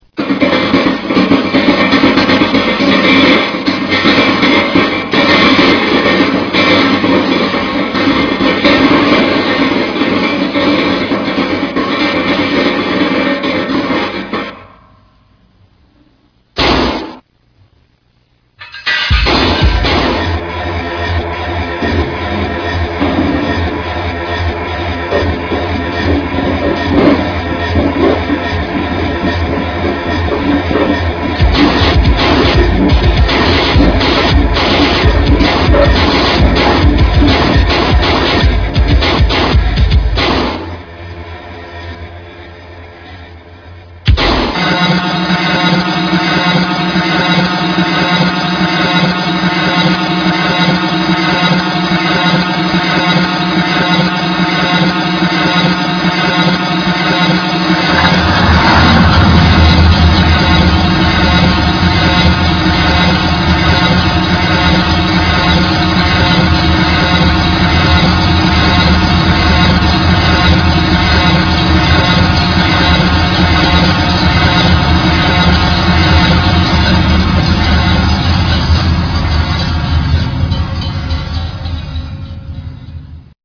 INDUSTRIAL ELECTRÓNICO